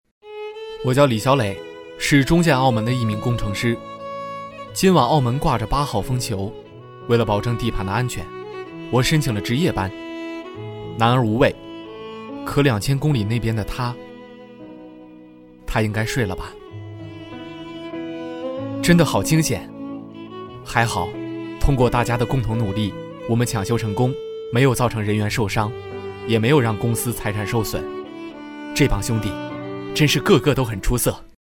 特点：年轻自然 素人 走心旁白
29男-微电影旁白-中海好男儿.mp3